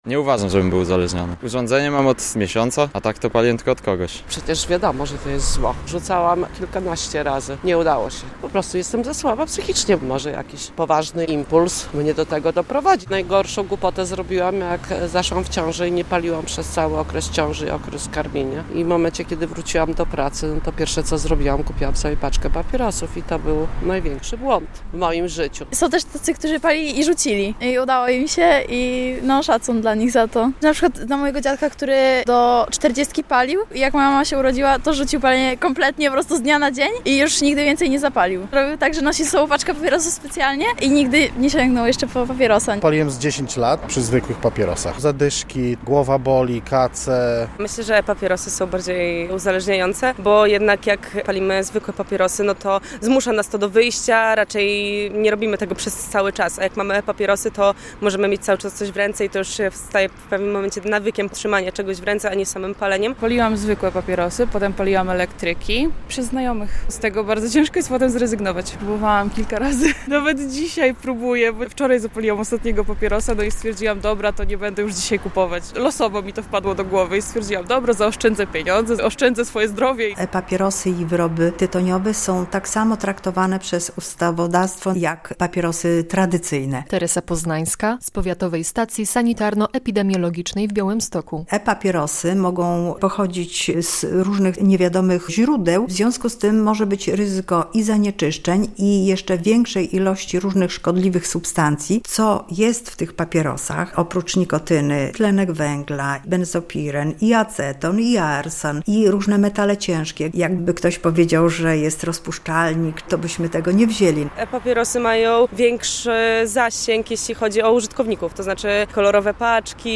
Zapytaliśmy mieszkańców Białegostoku o ich spostrzeżenia dotyczące palenia